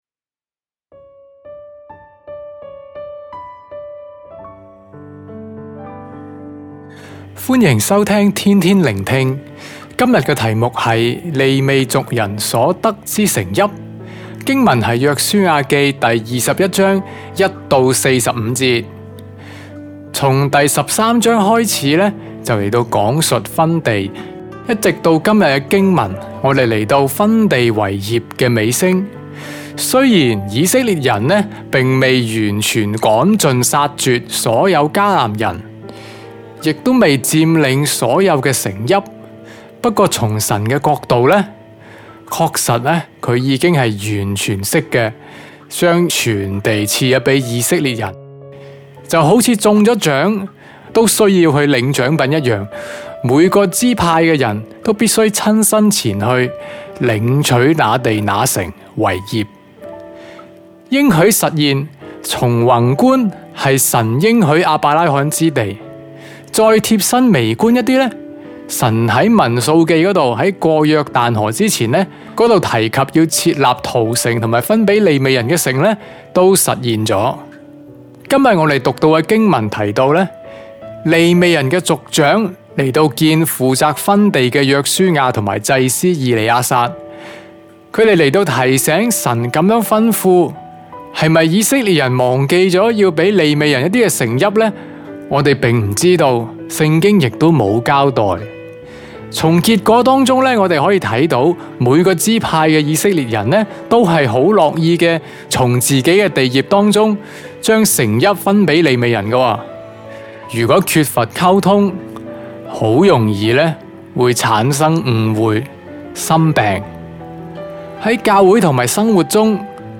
粵語錄音連結🔈